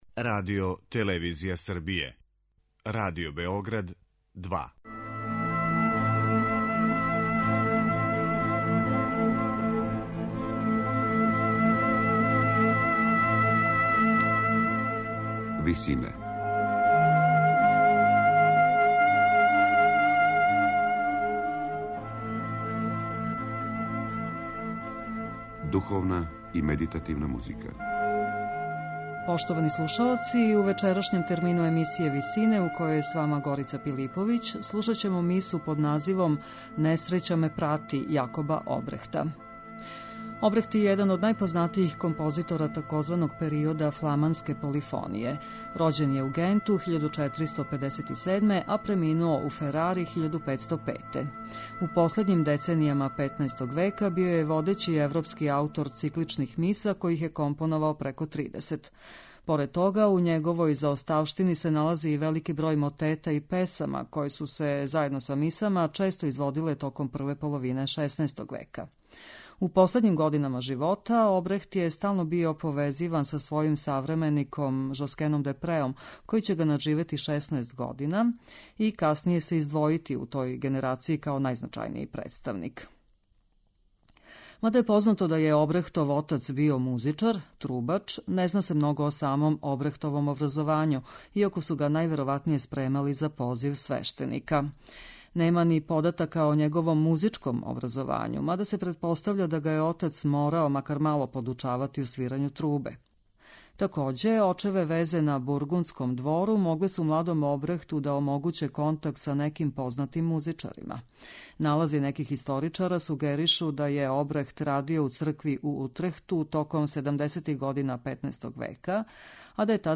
Поменута дела двојице аутора, припадника тзв. фламанске школе из XV века, испуниће емисију Висине.
Обрехт је један од најпознатијих композитора тзв. периода фламанске полифоније.